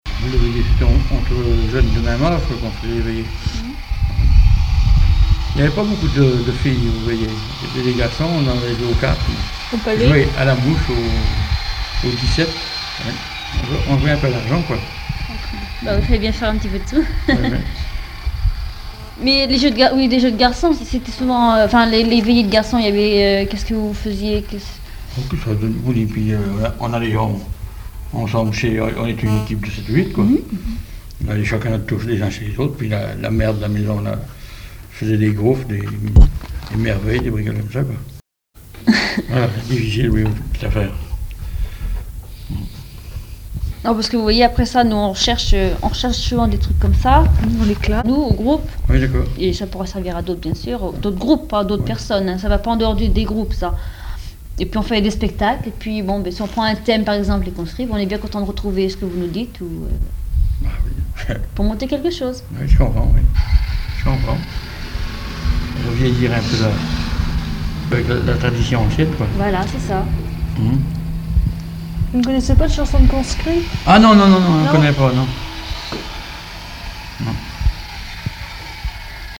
maison de retraite
Catégorie Témoignage